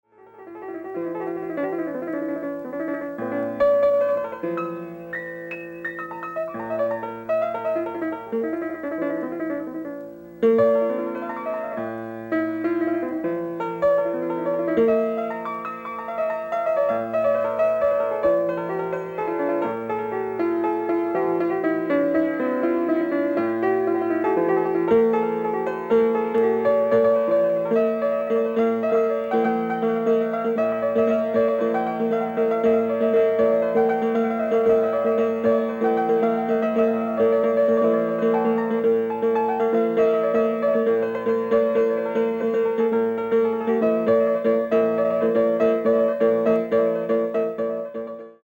ライブ・アット・ヴァッサー大学、キプシー、ニューヨーク
※試聴用に実際より音質を落としています。